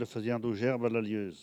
Localisation Sallertaine
Langue Maraîchin
Catégorie Locution